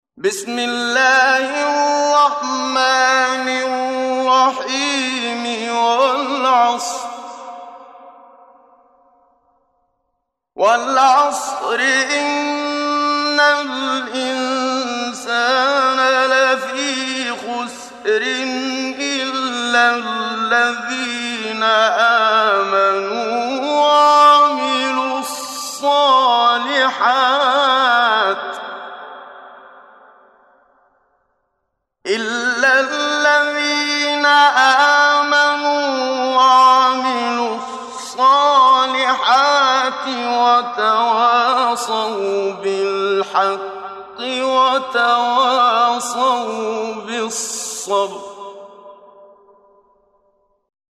محمد صديق المنشاوي – تجويد – الصفحة 9 – دعاة خير